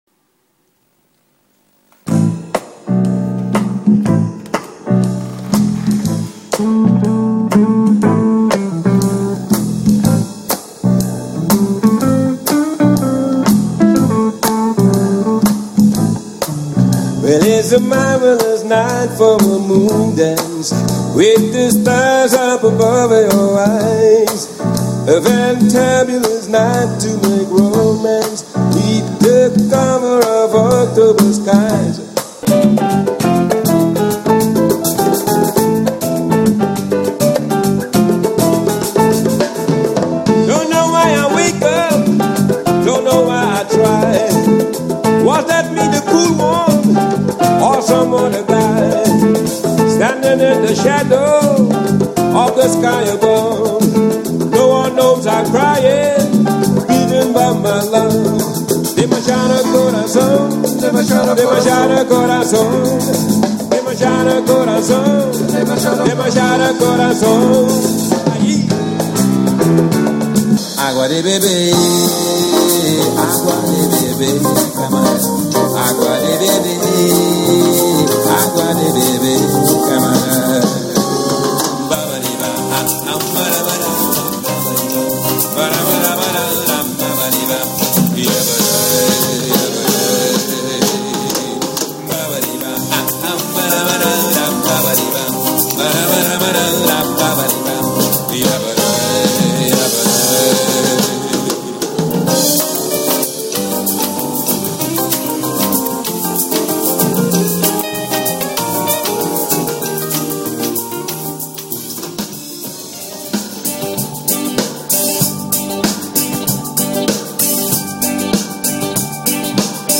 Life mobile recording IV